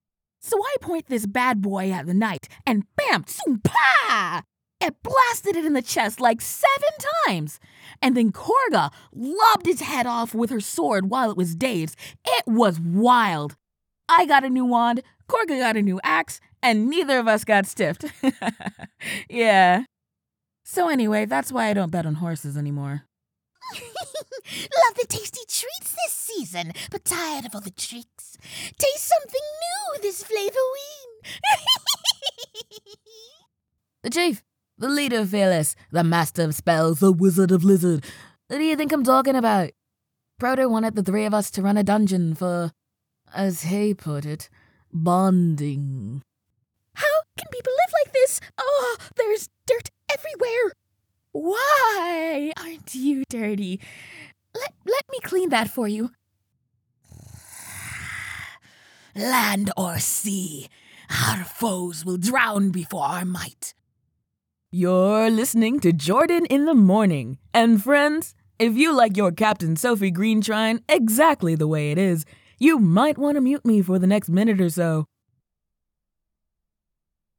A Warm Bubby Friend
Character Demo
General American